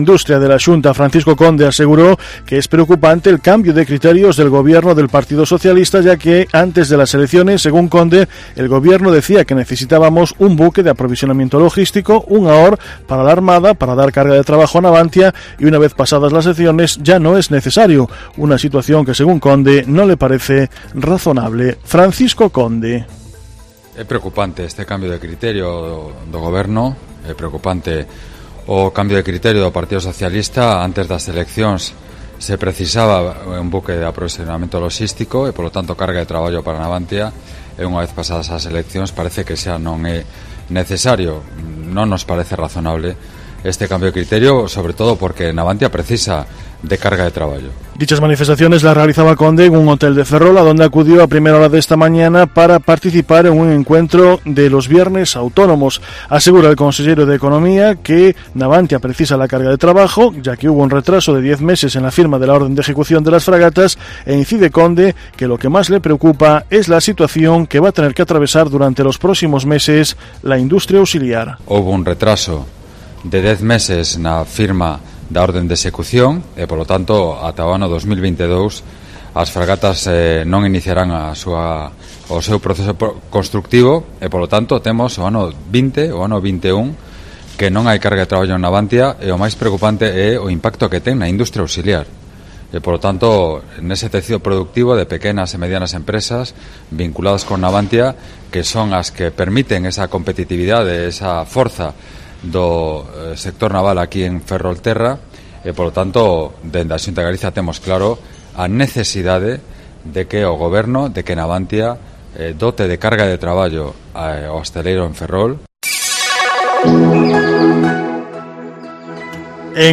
Informativo Mediodía Cope Ferrol 15/11/2019 (De 14.20 a 14.30 horas)